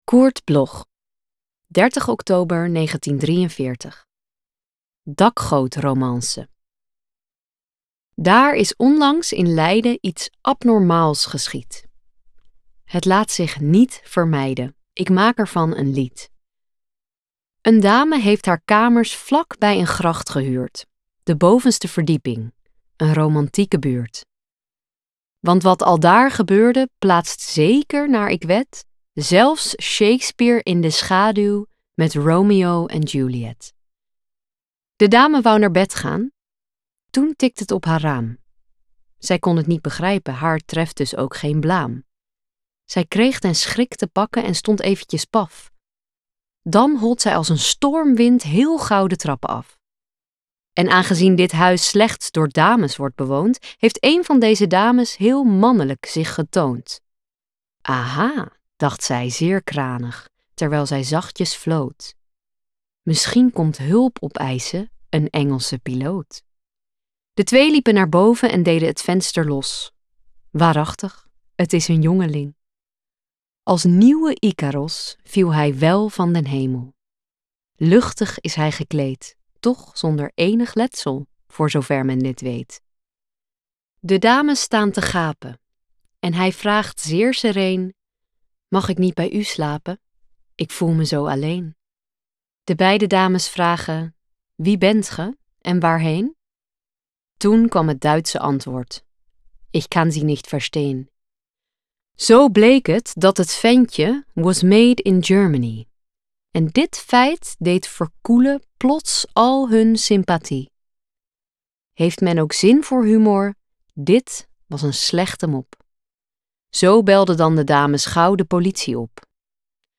Aufnahme: Karaktersound, Amsterdam · Bearbeitung: Kristen & Schmidt, Wiesbaden